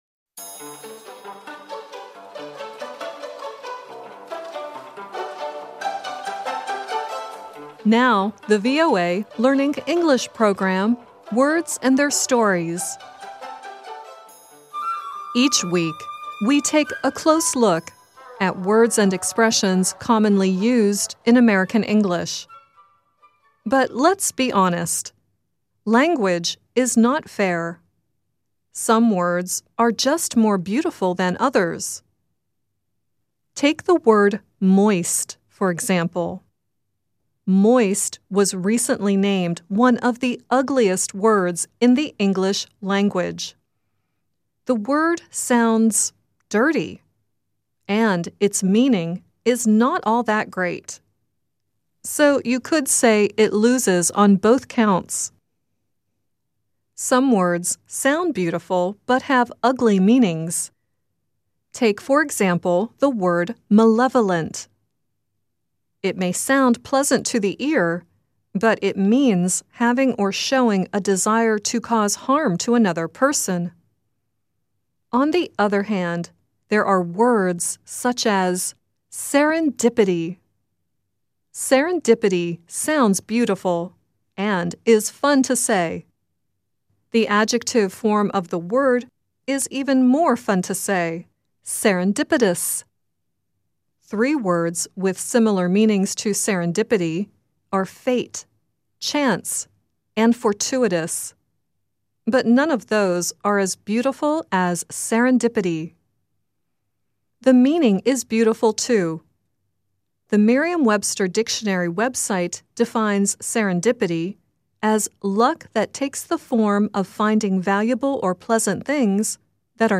The song at the end is the Cranberries singing "Serendipity."